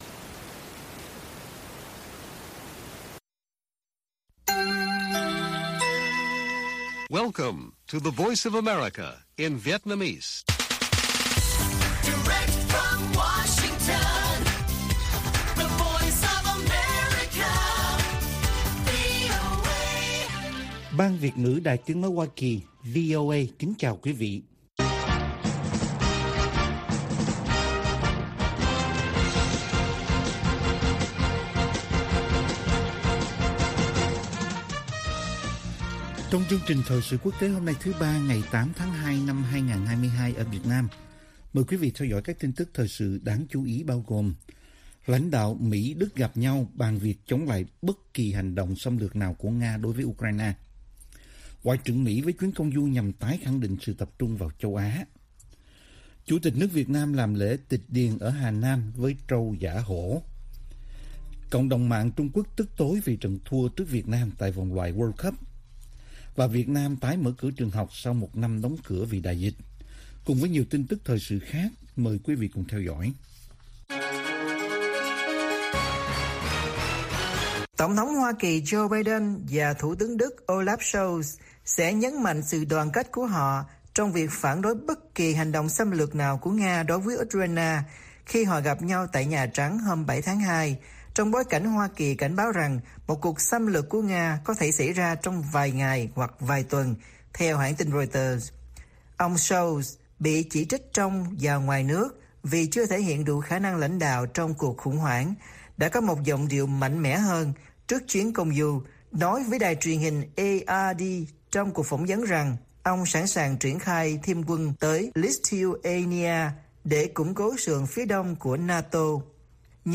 Bản tin VOA ngày 8/2/2022